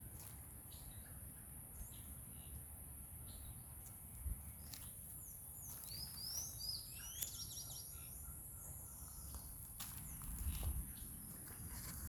Scientific name: Tityra cayana braziliensis
English Name: Black-tailed Tityra
Location or protected area: Reserva Privada San Sebastián de la Selva
Condition: Wild
Certainty: Photographed, Recorded vocal